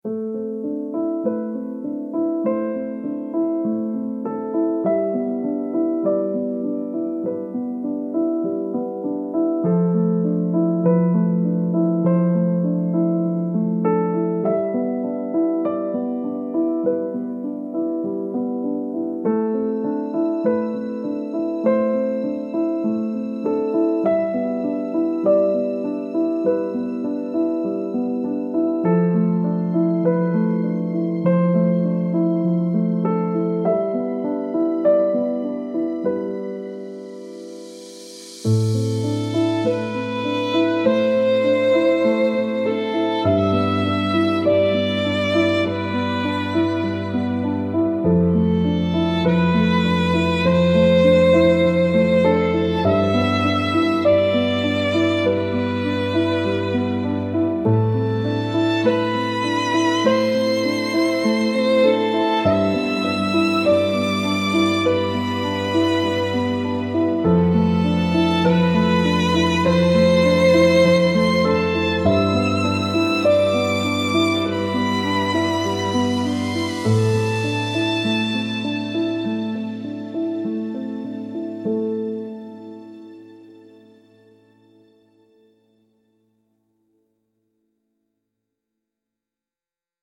timeless remembrance music with warm piano and soft violin harmony